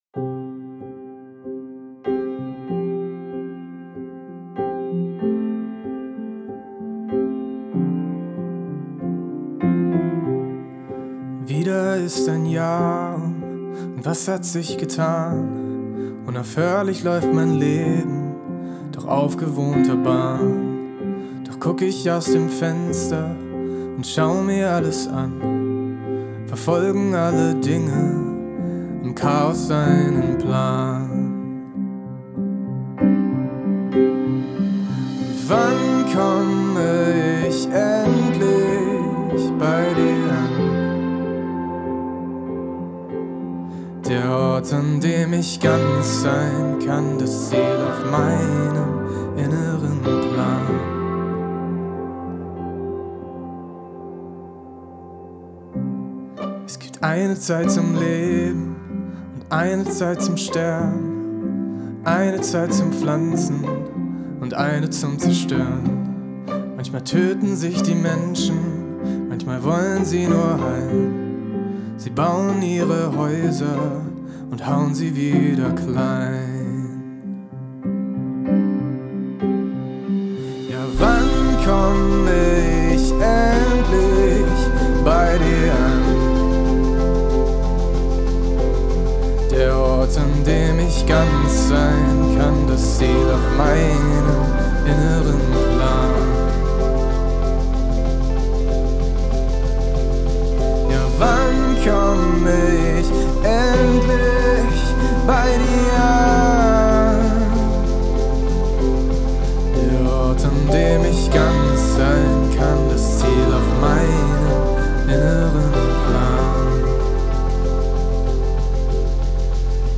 Dynamisches Pop-Epos (oder so)
🙂 Ich habe gestern Abend voller Schrecken festgestellt, dass Soundcloud den Ton ja übelst komprimiert. Das macht das Geschepper in den Höhen sicher nicht besser.
Außerdem hab ich in den Overheads ein paar Frequenzen rausgezogen.
Ich bin noch mal per Hand reingegangen und hab die Konsonanten, die mir zu stark schienen, leiser gemacht.